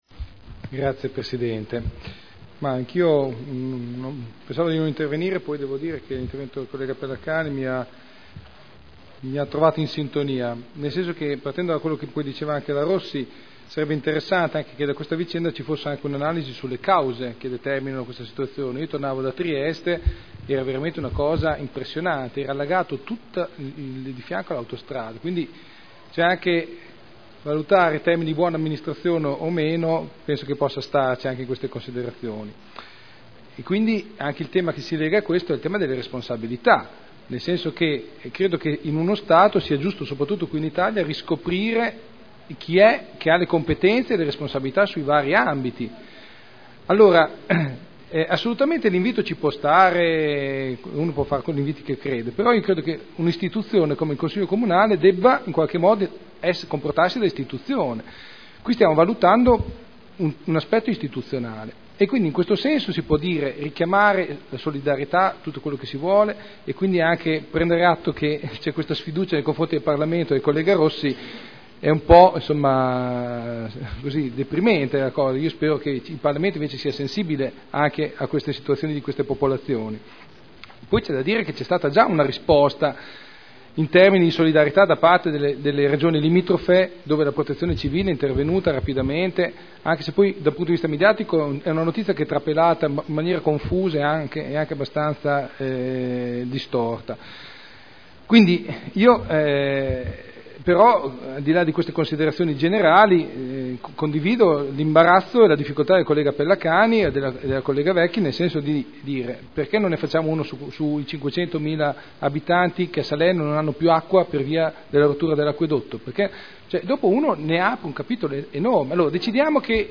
Enrico Artioli — Sito Audio Consiglio Comunale